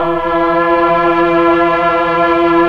Index of /90_sSampleCDs/Roland LCDP09 Keys of the 60s and 70s 1/PAD_Melo.Str+Vox/PAD_Tron Str+Vox